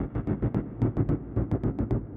Index of /musicradar/rhythmic-inspiration-samples/110bpm